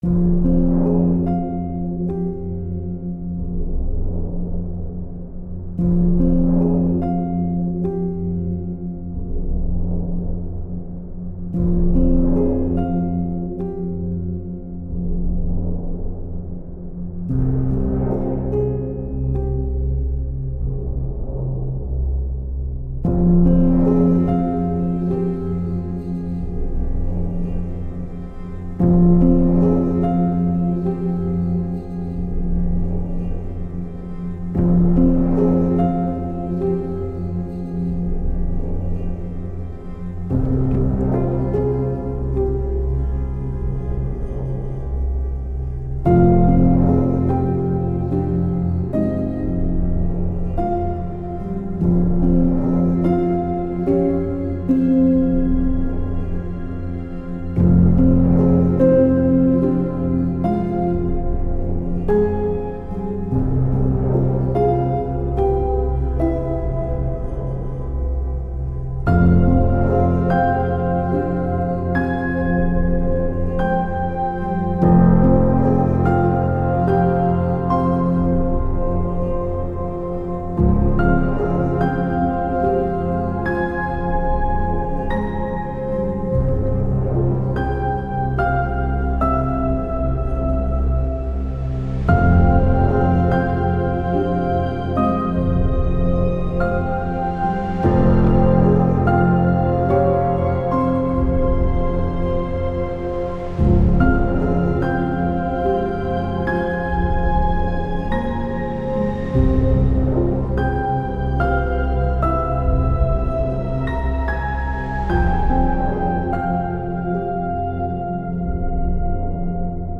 dark piano